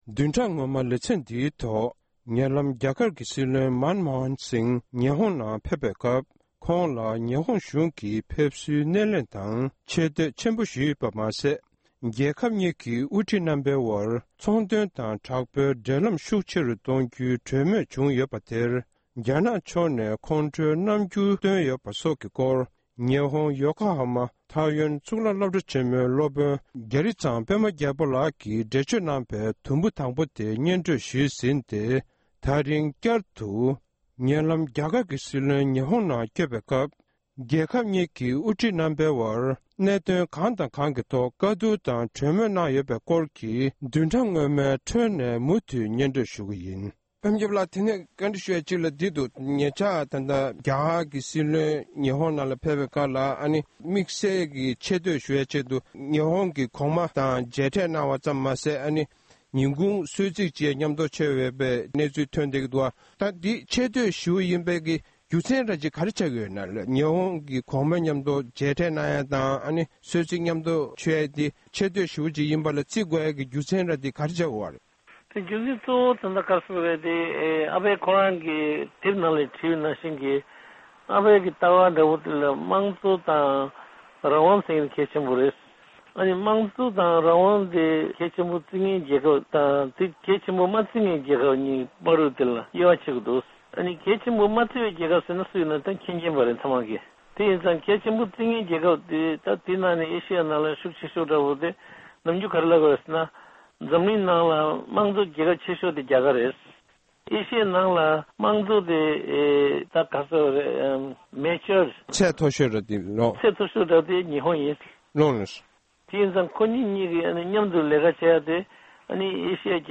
གནས་འདྲི་ཞུས་པར་མུ་མཐུད་ནས་གསན་རོགས༎